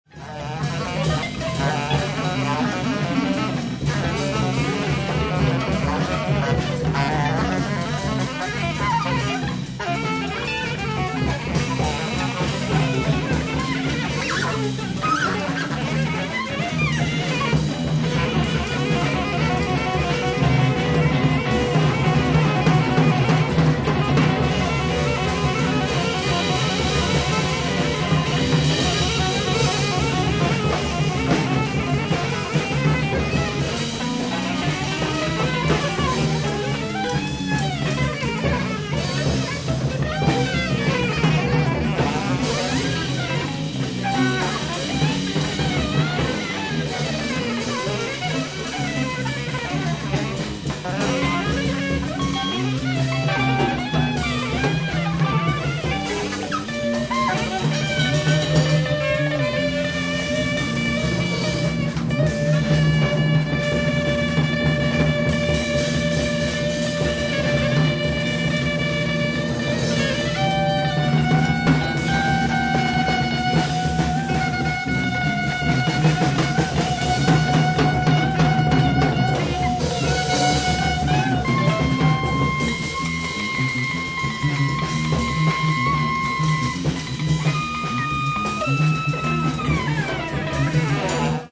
--- treibend, vielschichtig, transzendent
sax
guitar
bass